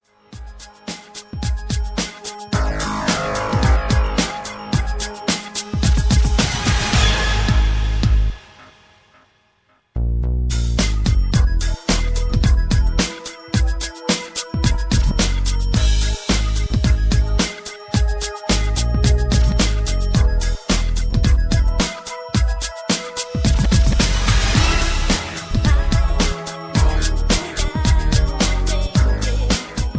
rock and roll